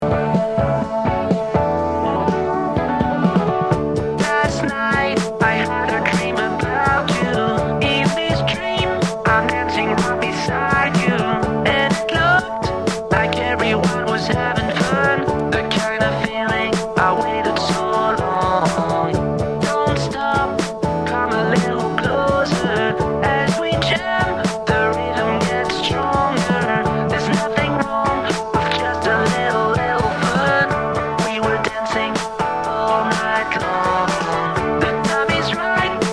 超胸キュンなロボ声ヴォーカルにBACK TO 1982な甘酸っぱいメロディ！
Tag       OTHER ROCK/POPS/AOR